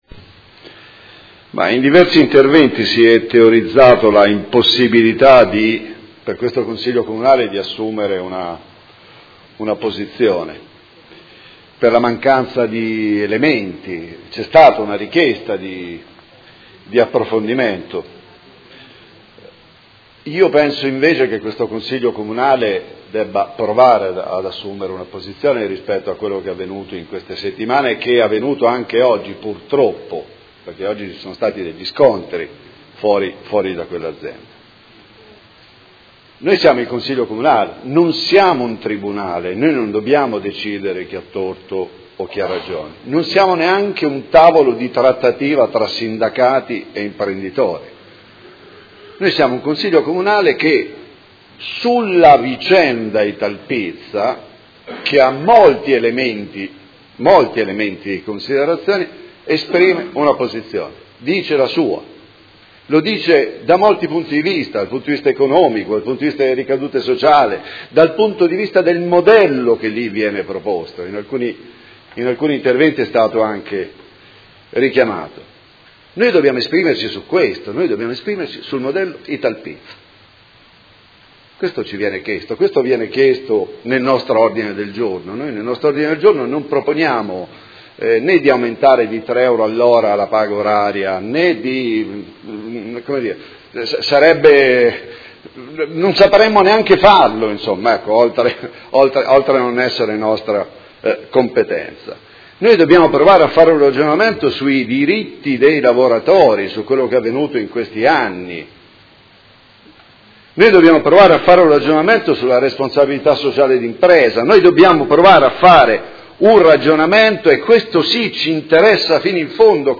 Seduta del 24/01/2019. Dibattito su interrogazione, mozioni ed emendamento riguardanti la situazione Società Italpizza S.p.A